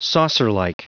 Prononciation du mot saucerlike en anglais (fichier audio)
Prononciation du mot : saucerlike